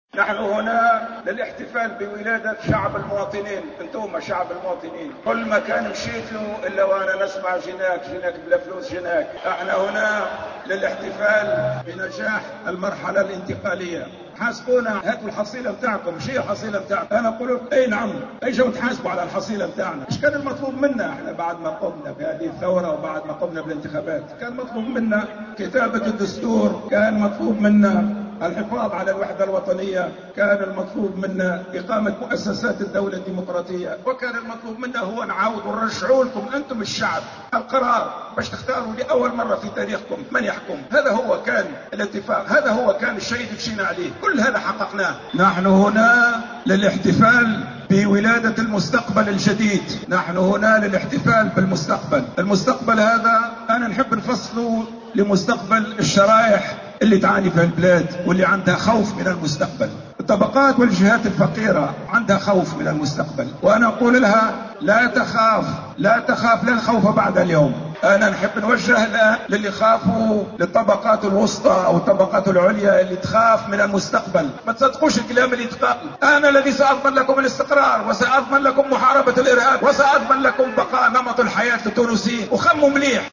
قال المترشح للانتخابات الرئاسية محمد المنصف المرزوقي خلال إجتماع شعبي في القبة إن هذا الاجتماع مخصص للاحتفال بنجاح المرحلة الانتقالية ،مضيفا أنه سيكون الضامن للإستقرار ومحاربة الارهاب و المحافظة على نمط عيش التونسيين .